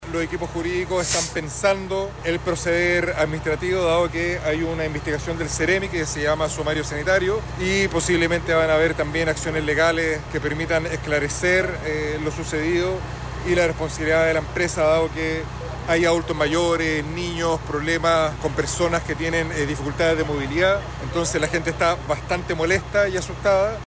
El alcalde de La Cisterna, Joel Olmos, sostuvo que el equipo jurídico municipal se encuentra evaluando acciones legales contra la empresa por esta situación.